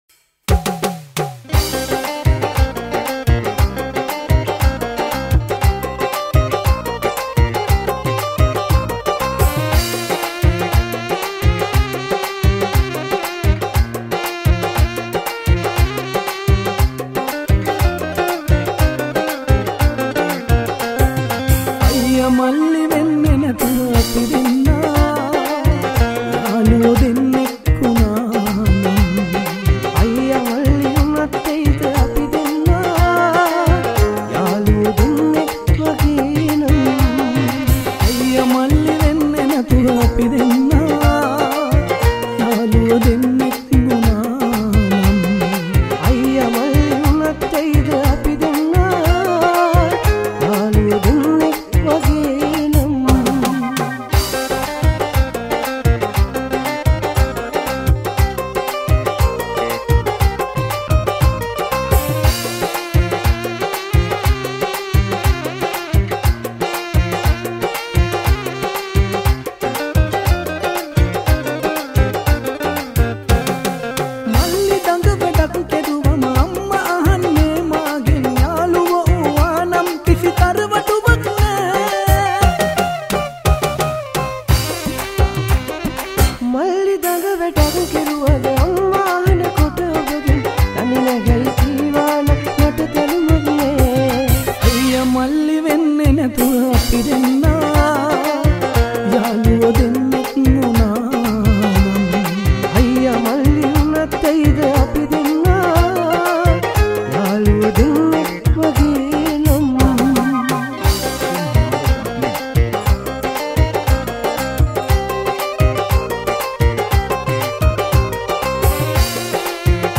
Category: Live Shows